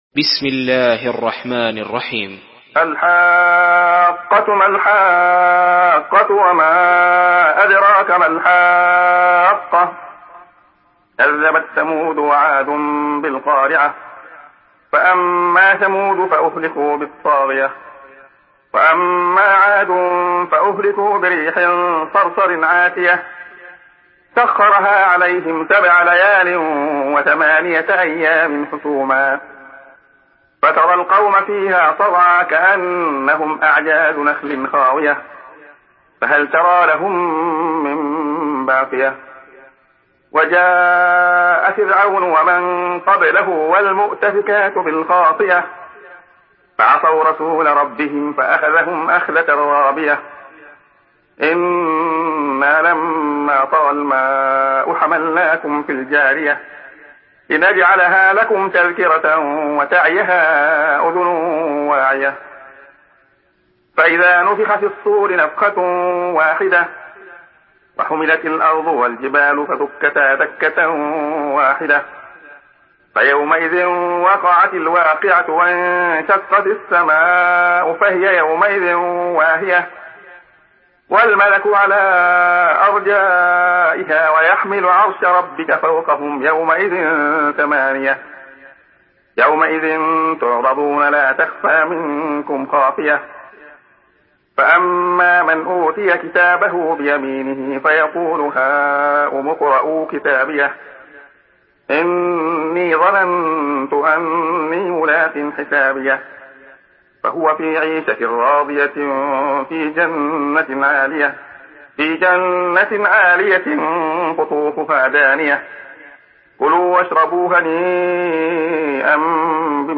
Surah Al-Haqqah MP3 by Abdullah Khayyat in Hafs An Asim narration.
Murattal Hafs An Asim